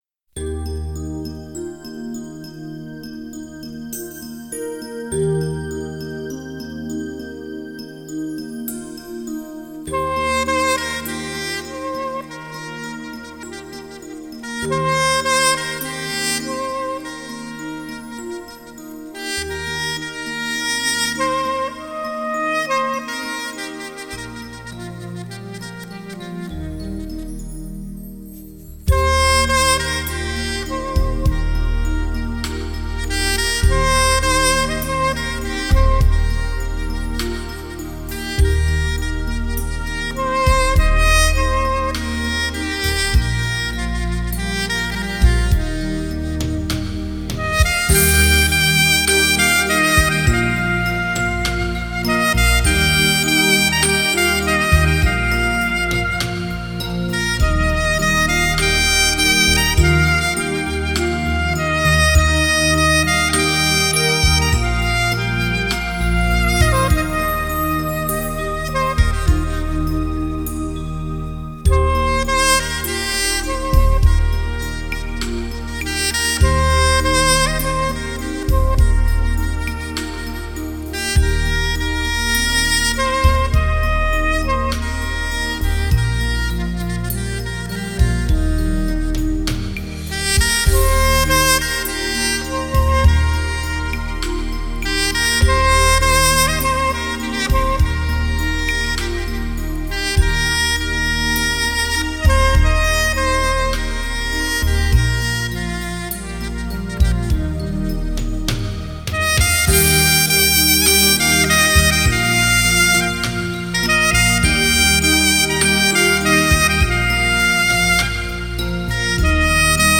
2周前 纯音乐 11